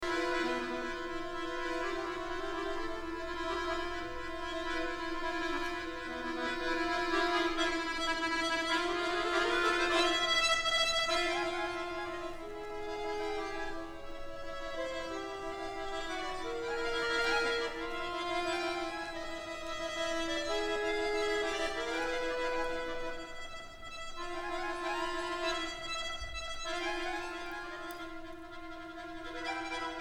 eTrack Music from the 2009 Coupe Mondiale Finale Concert